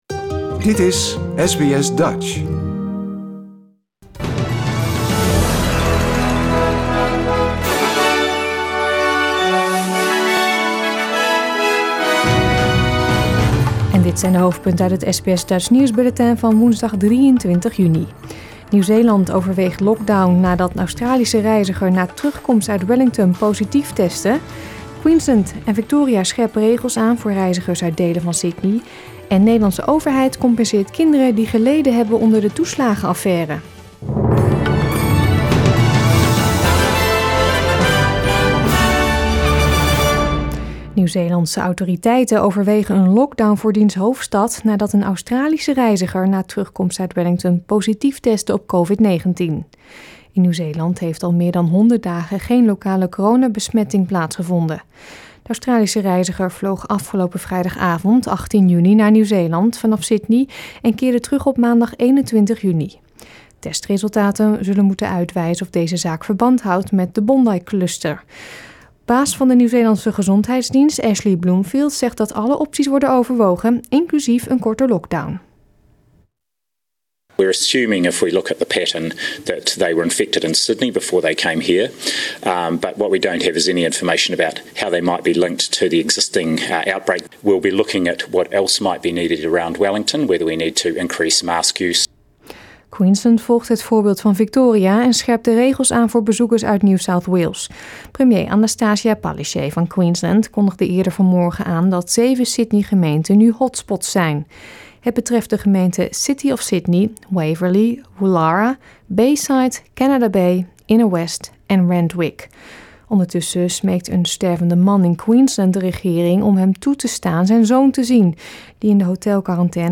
Nederlands/Australisch SBS Dutch nieuwsbulletin van woensdag 23 juni 2021